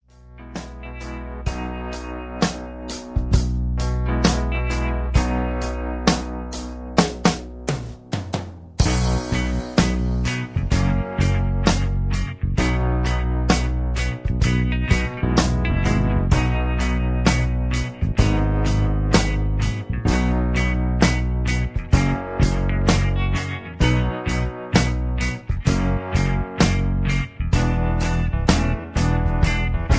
karaoke
country rock